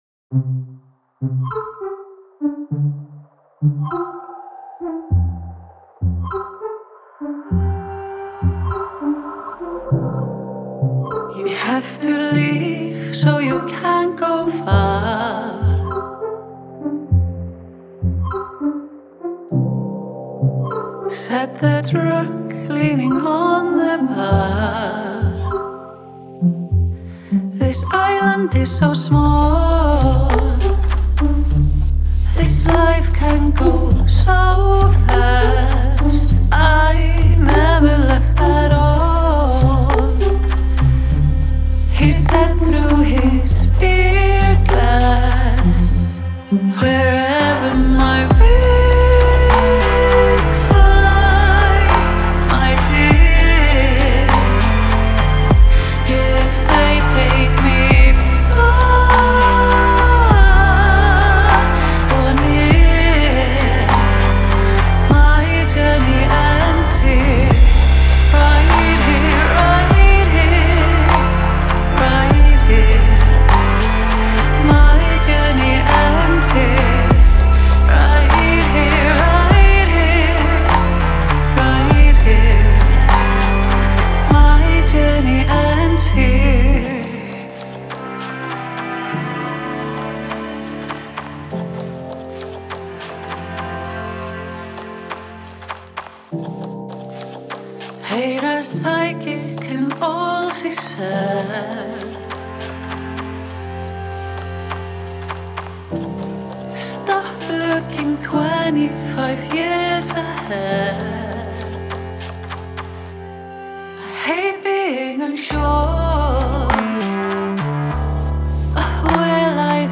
é uma composição guiada por sintetizadores e cordas